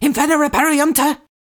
🌲 / foundry13data Data modules psfx library incantations older-female fire-spells inferna-aperiuntur
inferna-aperiuntur-rushed.ogg